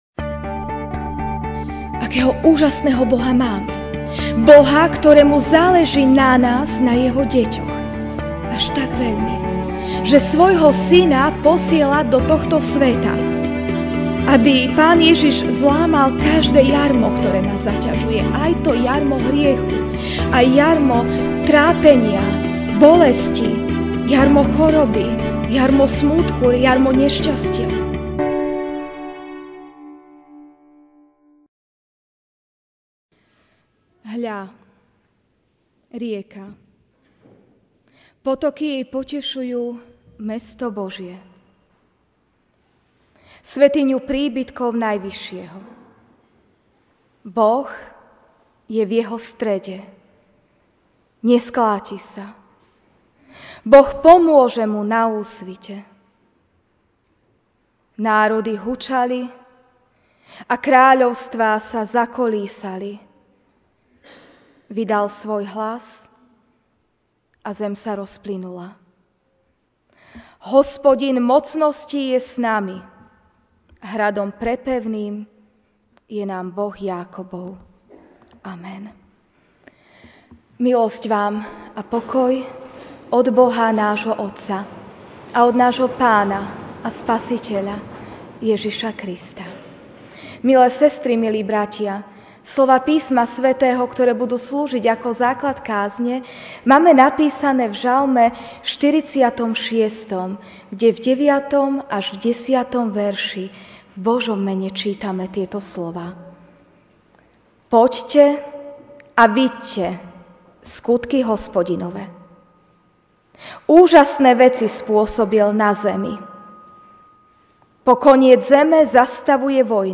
Ranná kázeň: Boh zastavuje (Ž 46, 9-10)Poďte a hľaďte na skutky Hospodinove!Úžasné veci spôsobil na zemi!